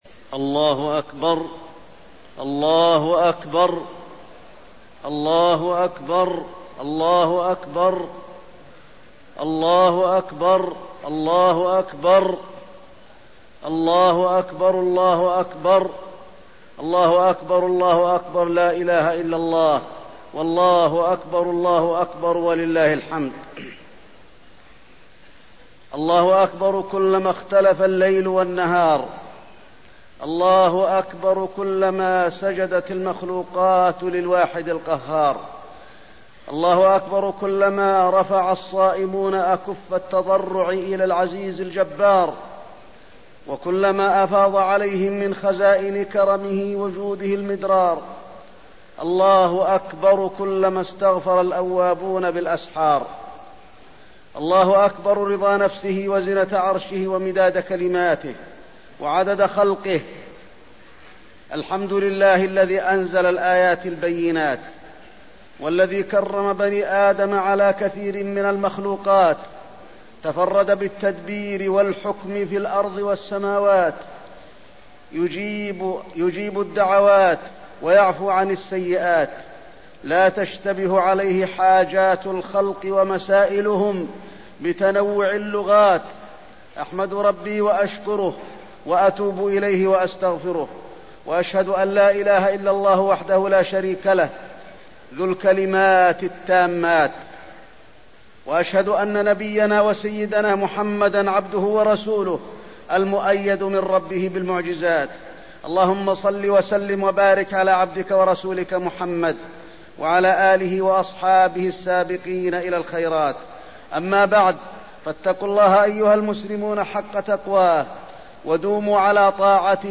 خطبة عيد الأضحى - المدينة - الشيخ علي الحذيفي